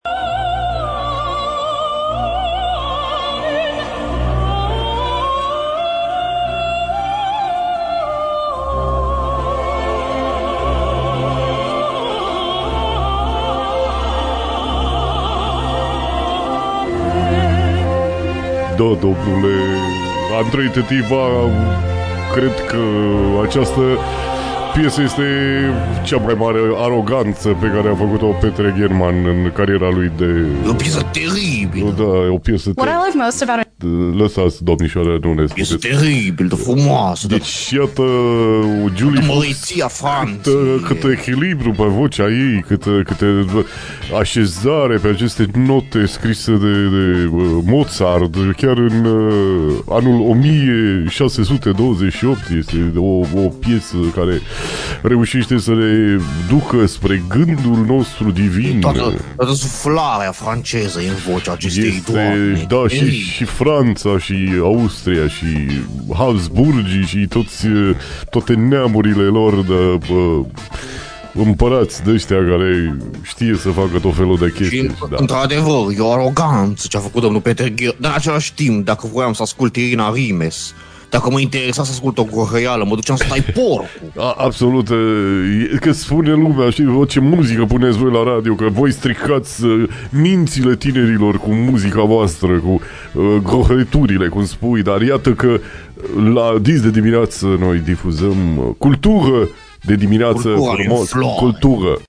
PARODIE / Iosif Sava și Radu Banciu comentează Mozart, la DIS DE DIMINEAȚĂ
Iosif Sava și Radu Banciu s-au întâlnit în studioul de IMPACT, la modul ideatic…:)), chiar la DIS DE DIMINEAȚĂ, comentând un fragment din opera lui Wolfgang Amadeus Mozart.